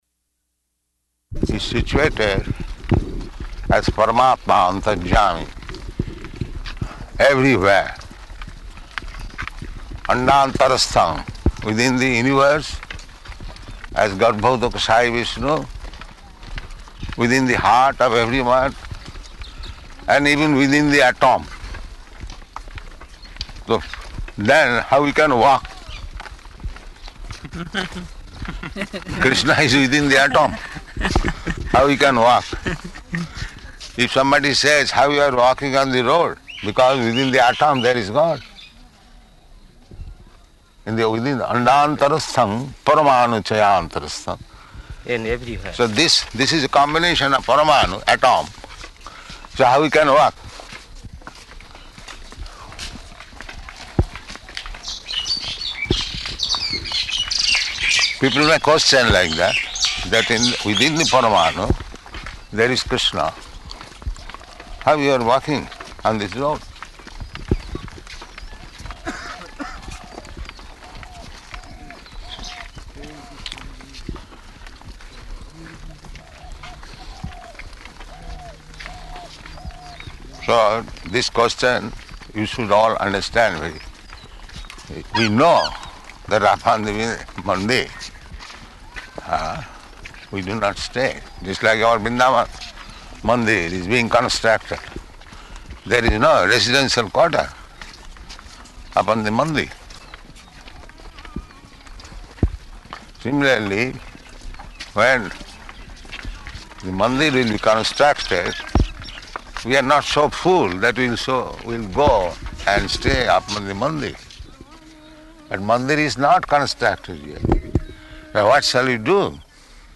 Morning Walk --:-- --:-- Type: Walk Dated: March 9th 1974 Location: Māyāpur Audio file: 740309MW.MAY.mp3 Prabhupāda: He's situated as Paramātmā, antaryāmī, everywhere.